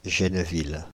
Genneville (French pronunciation: [ʒɛnvil]
Fr-Genneville.ogg.mp3